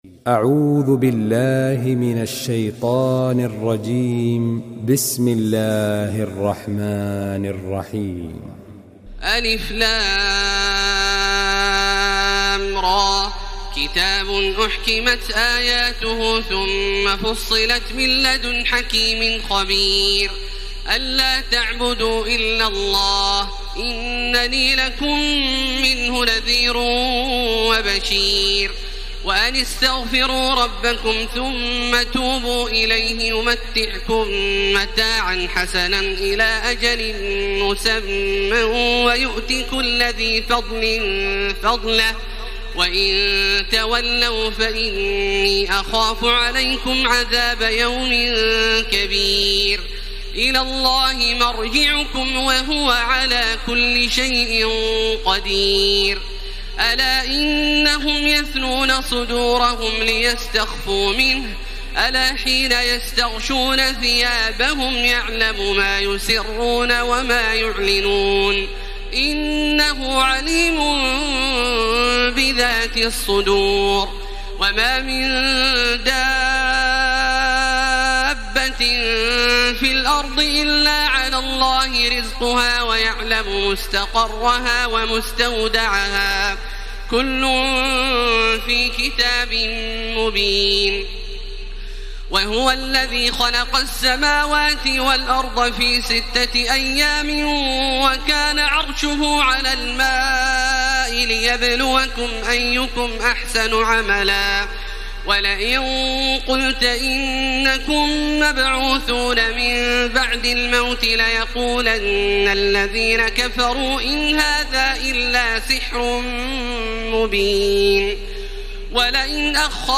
تراويح الليلة الحادية عشر رمضان 1434هـ من سورة هود (1-83) Taraweeh 11 st night Ramadan 1434H from Surah Hud > تراويح الحرم المكي عام 1434 🕋 > التراويح - تلاوات الحرمين